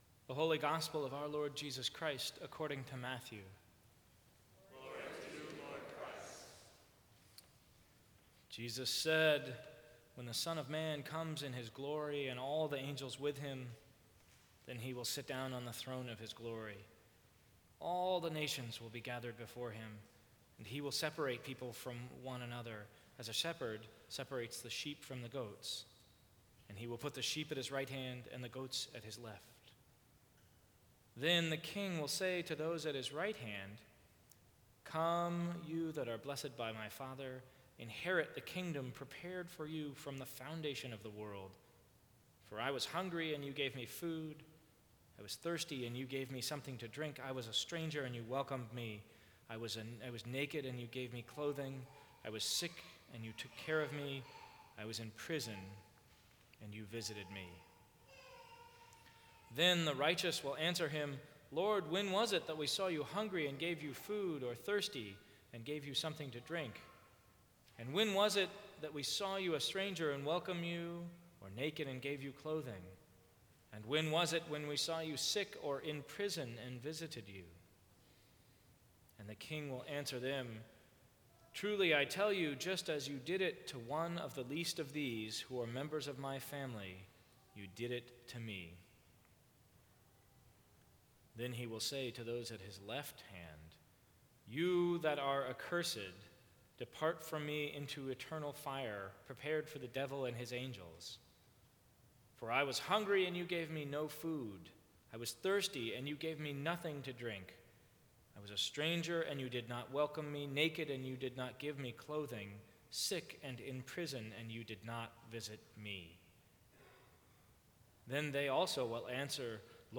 Sermons from St. Cross Episcopal Church November 23, 2014.